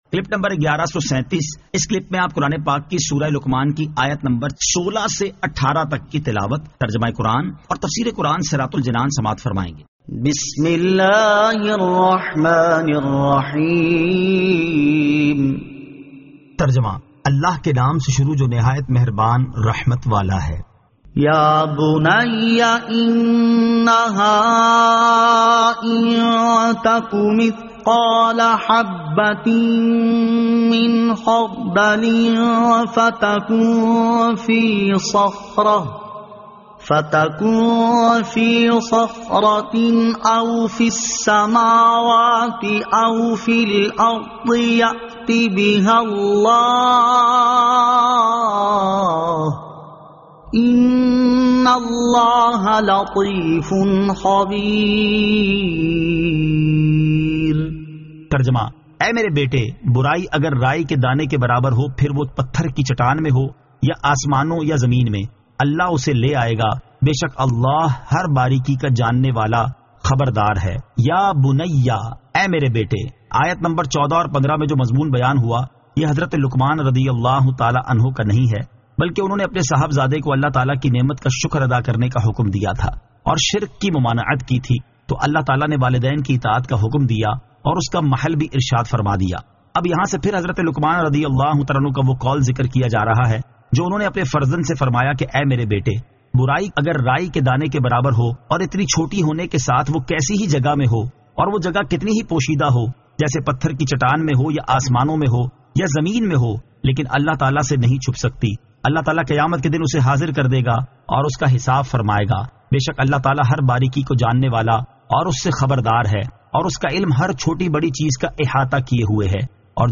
Surah Luqman 16 To 18 Tilawat , Tarjama , Tafseer